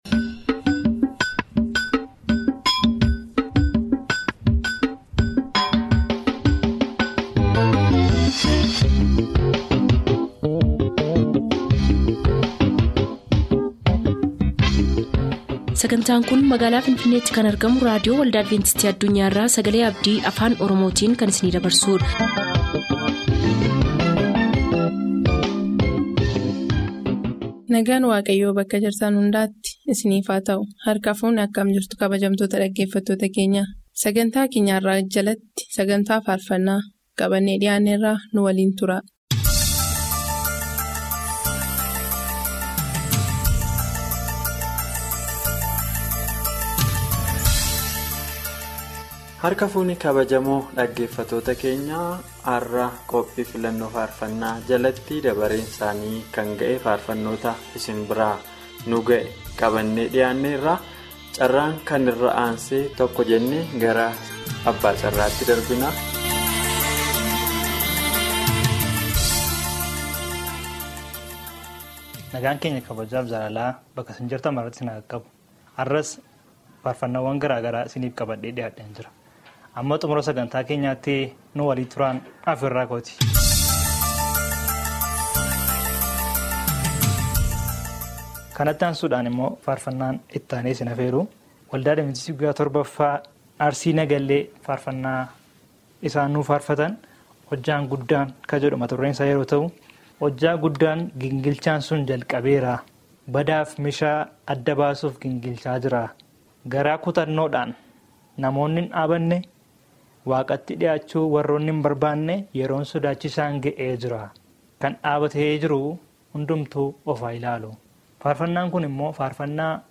WITTNESING AND TODAY’S SERMON